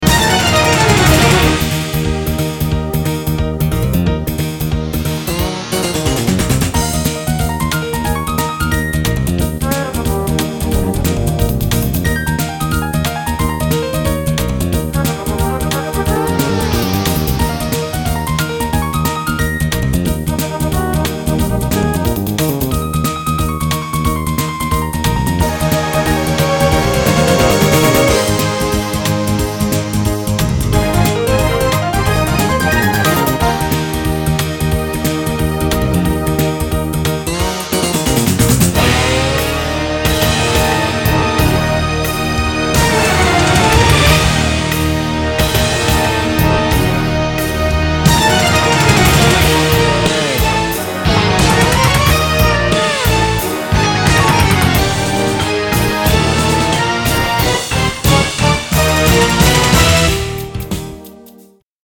Jazzy Piano